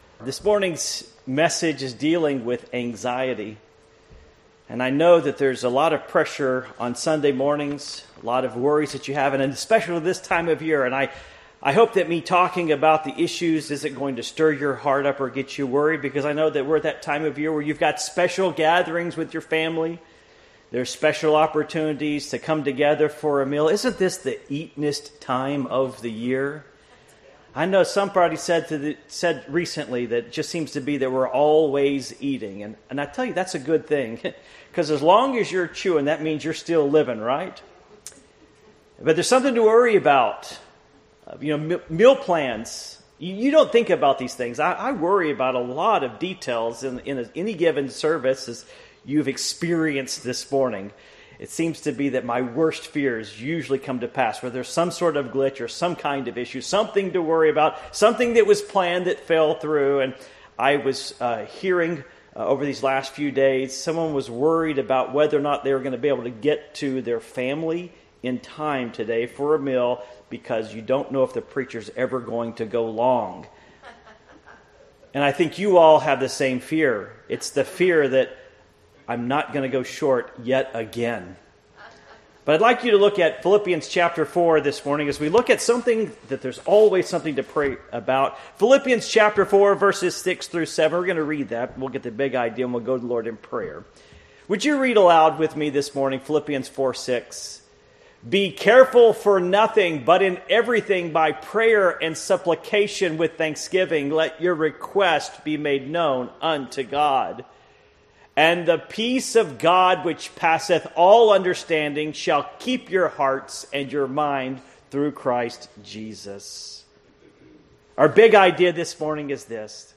Series: The Ministry of the Encourager Passage: Philippians 4:6-7 Service Type: Morning Worship